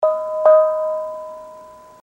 sound it makes